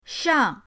shang4.mp3